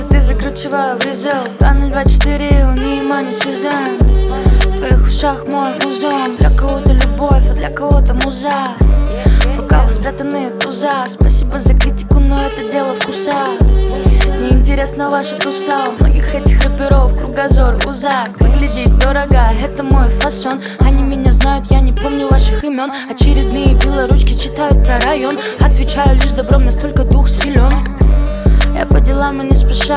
# Hip-Hop